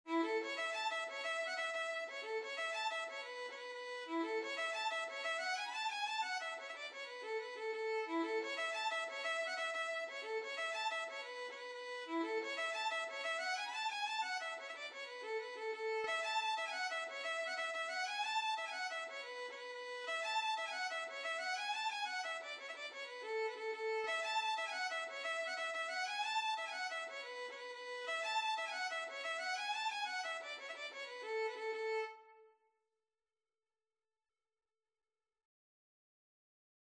6/8 (View more 6/8 Music)
A major (Sounding Pitch) (View more A major Music for Violin )
Violin  (View more Intermediate Violin Music)
Traditional (View more Traditional Violin Music)
Irish